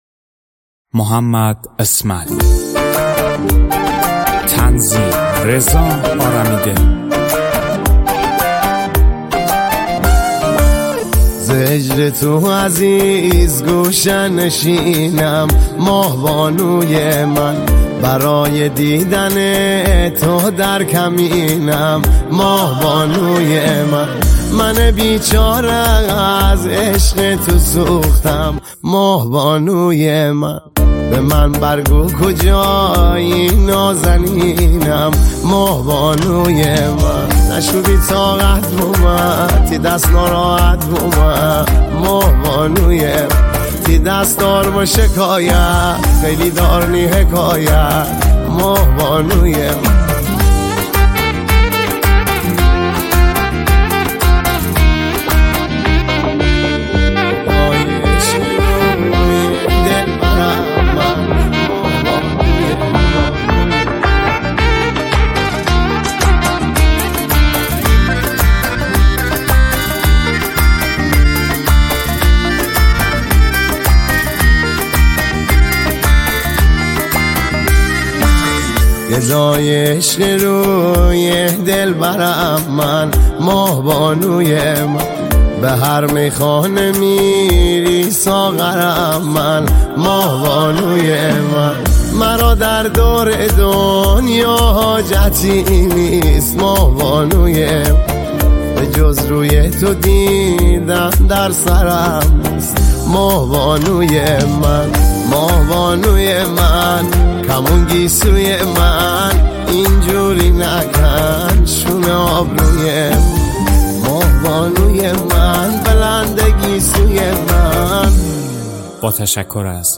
ریتمیک ( تکدست )
موزیک زیبای مازندرانی
آهنگی در سبک آهنگ های ریتمیک مازندرانی